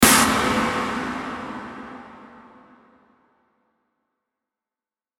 Download Hit sound effect for free.
Hit